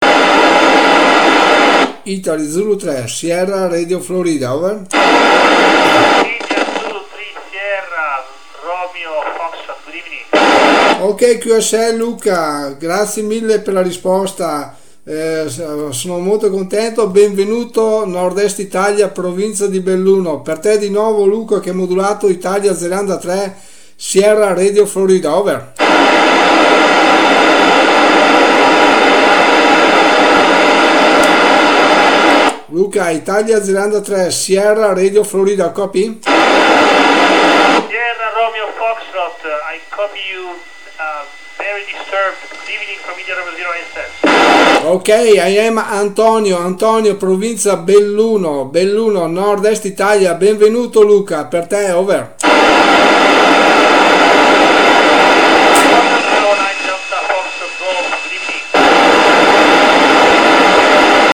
Working Conditions: Icom IC-821H 35w and 5 elements Yagi antenna. RS 59.